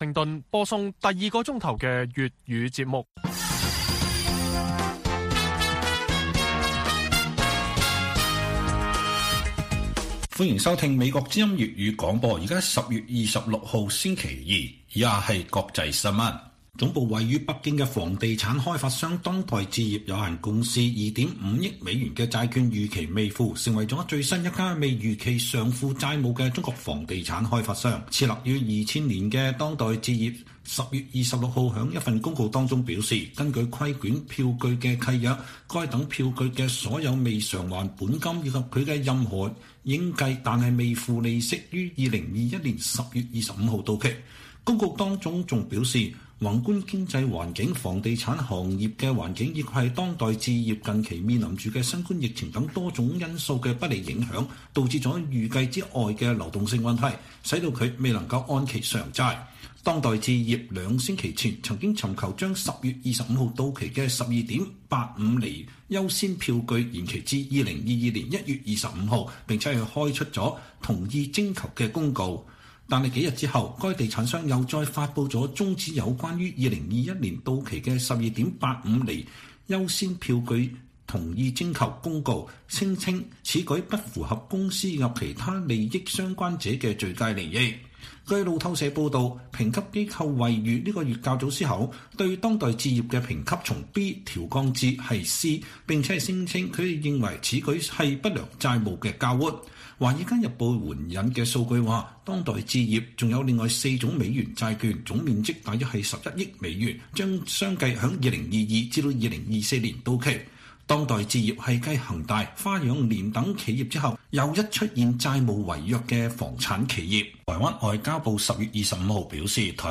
粵語新聞 晚上10-11點: 中國房產商當代置業美元債券到期未付